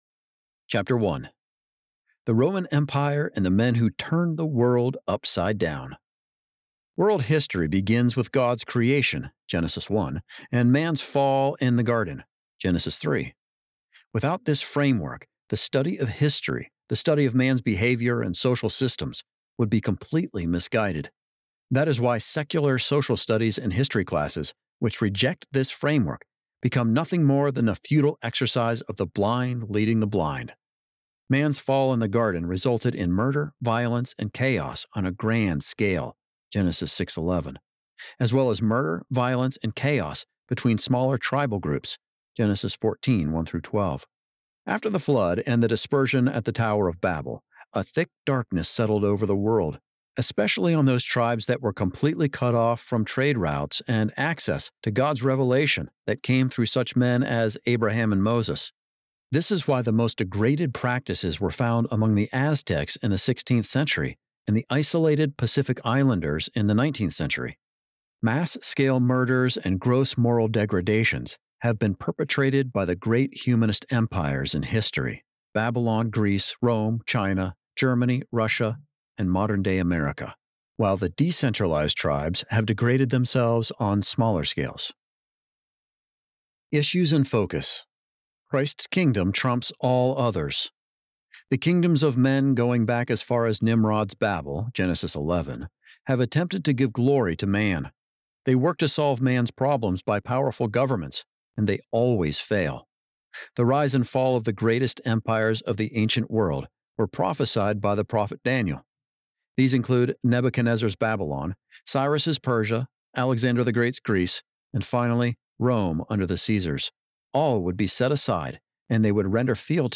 Audiobook Download, 21 hours 30 minutes